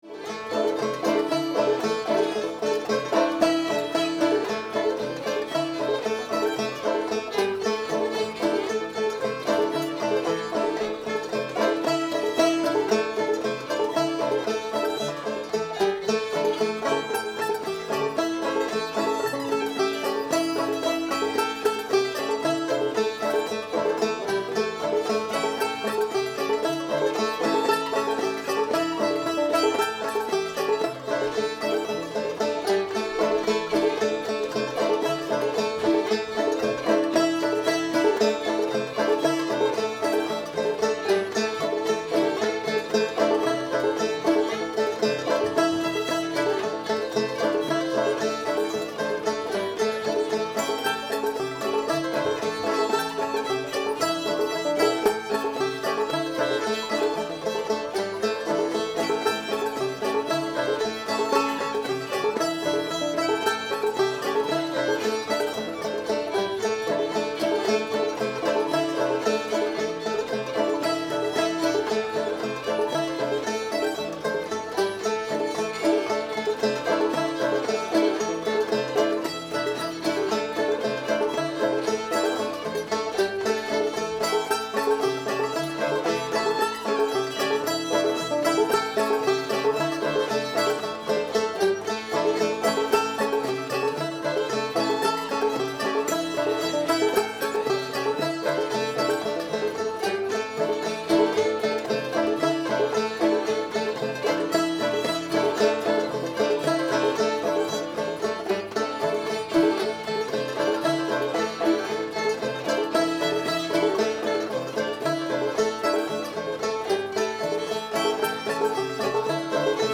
lonesome john [A modal]